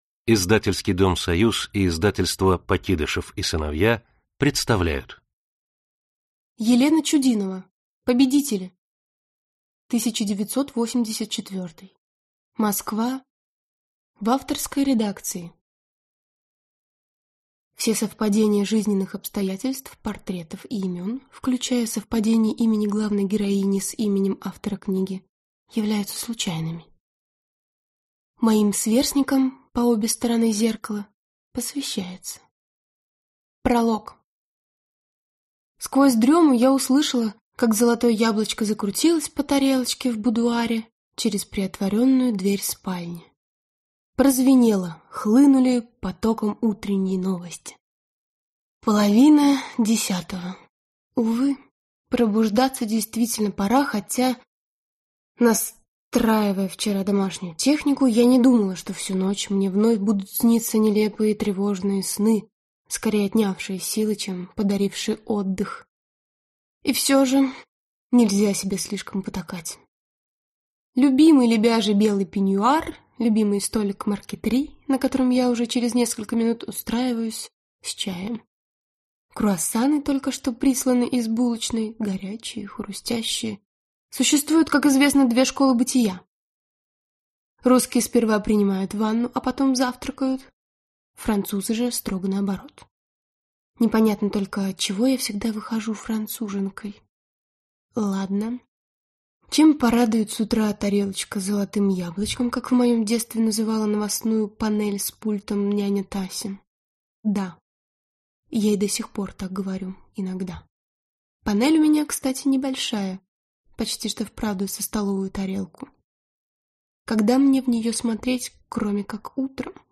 Аудиокнига Победители 1984 | Библиотека аудиокниг